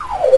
more sound effects
backpack_close.ogg